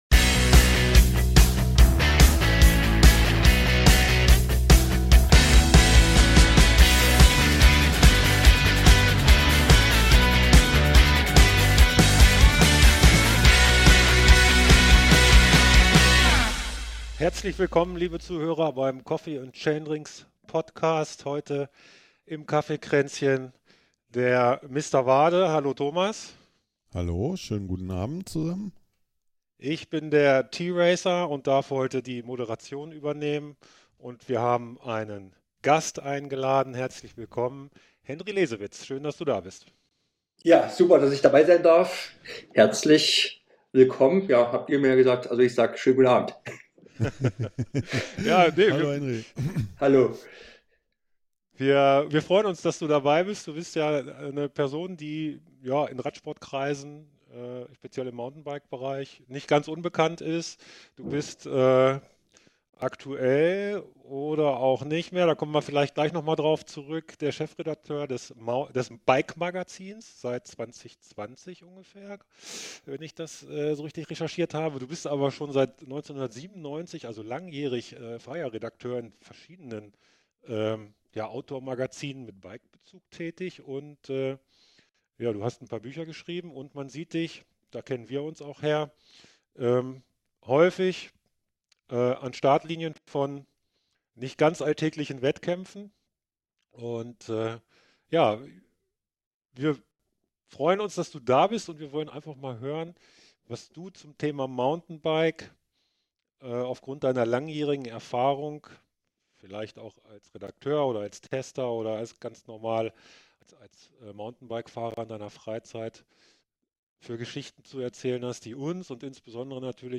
In dieser Podcast-Folge lässt er uns in seiner eloquenten, sympathischen Art an seinen Erlebnissen teilhaben.